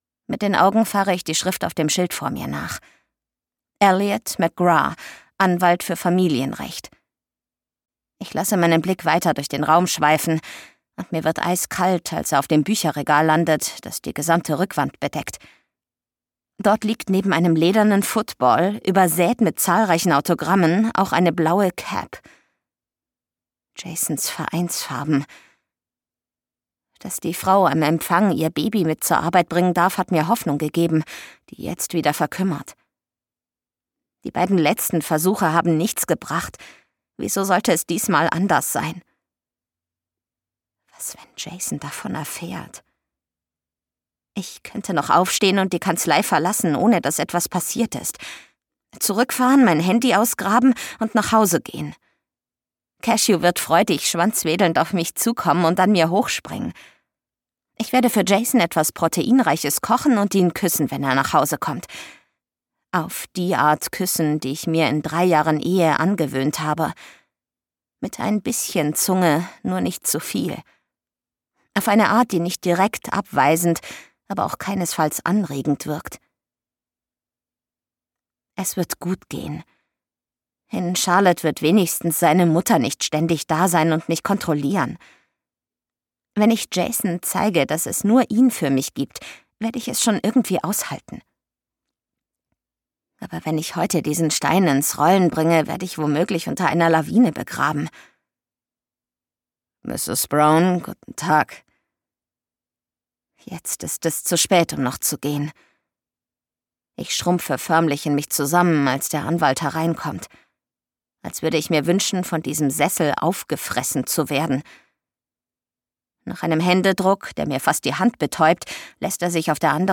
Zu diesem Hörbuch gibt es Zusatzmaterial.
Gekürzt Autorisierte, d.h. von Autor:innen und / oder Verlagen freigegebene, bearbeitete Fassung.